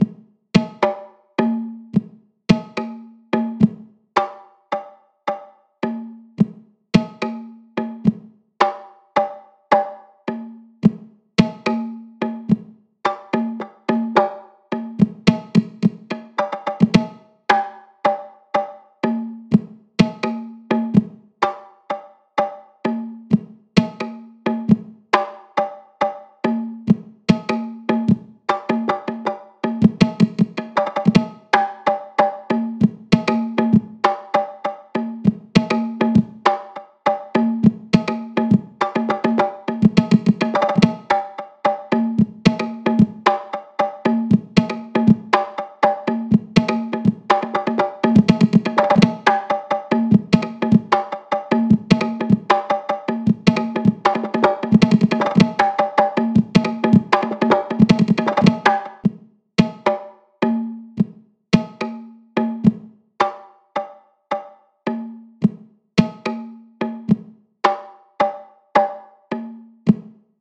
Drum Modelling Examples
tabla2 hand flam
tabla2 hand flam.mp3